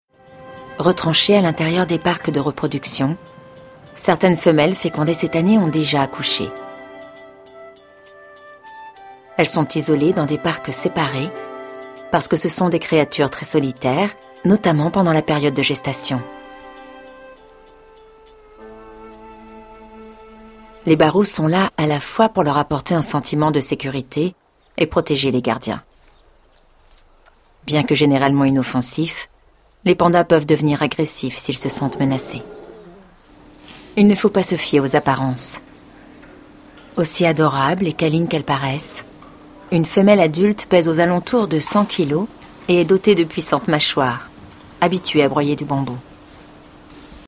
Voix-off féminine pro, voix fraiches, mutines, complices ou chantantes.
Documentaires / eBookDroite / ReportageNarrative / DoublageNaturelle / Rêveuse
Documentaires / eBook Droite / Reportage Narrative / Doublage Naturelle / Rêveuse Réserve du Wolong télécharger la voix (clique droit + enregistrer sous) narration // tranquille Réserve du Wolong remonter